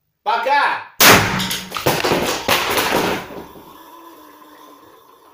Звук атаки